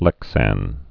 (lĕksăn)